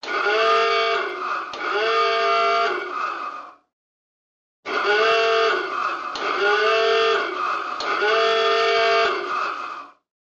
Тревожный сигнал на подводной лодке